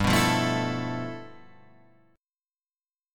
G13 chord